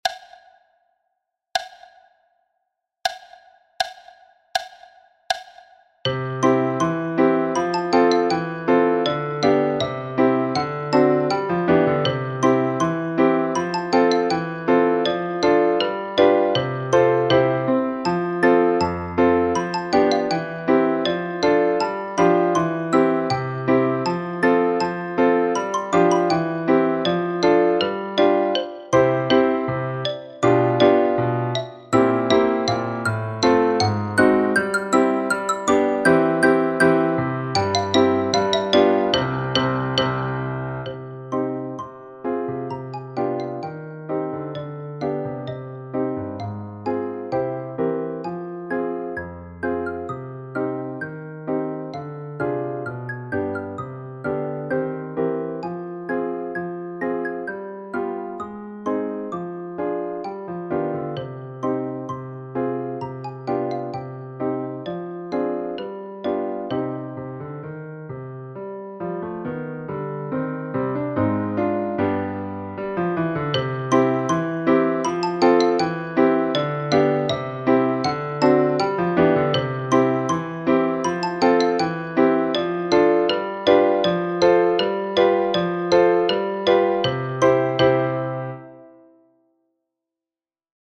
Après la pluie – vo – 80 bpm